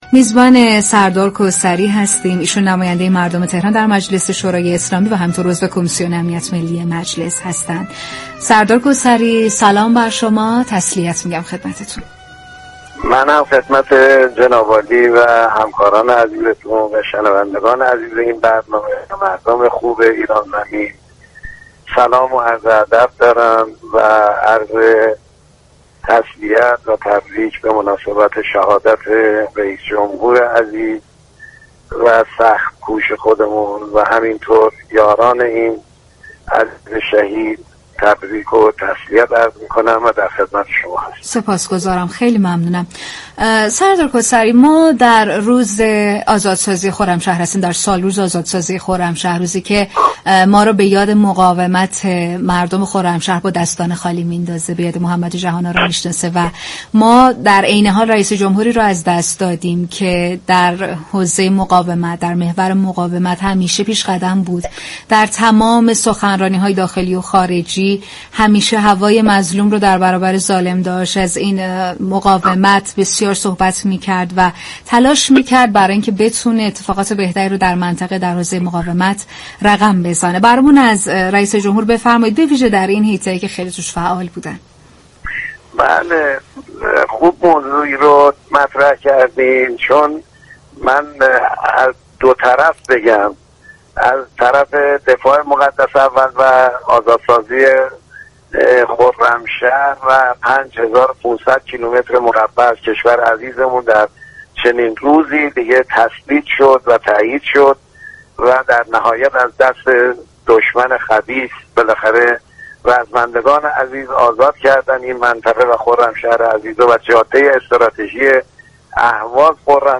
به گزارش پایگاه اطلاع رسانی رادیو تهران، اسماعیل كوثری عضو كمیسیون امنیت ملی مجلس شورای اسلامی در گفت و گو با ویژه برنامه «شهید خدمت» اظهار داشت: امروز سوم خرداد سالروز آزادسازی خرمشهر است؛ مدیریت جهادی منجر به موفقیت و آزادسازی خرمشهر شد.